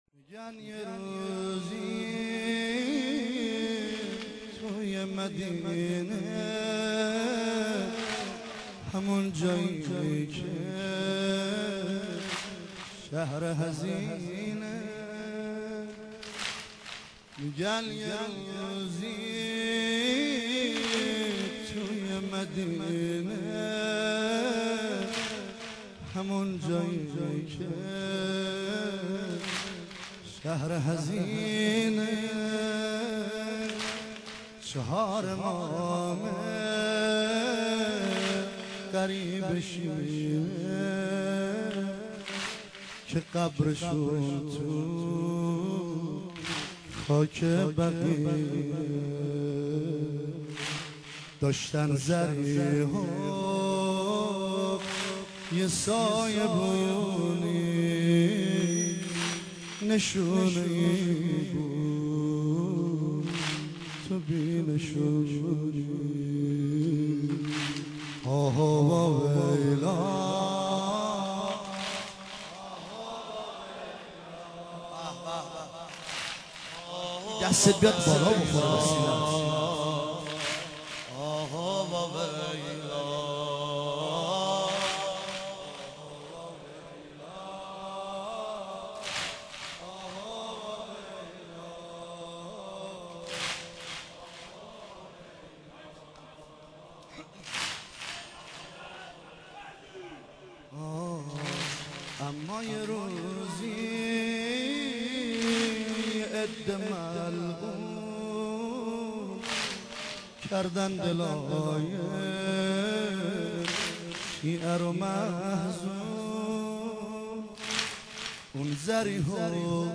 متن سینه زنی واحد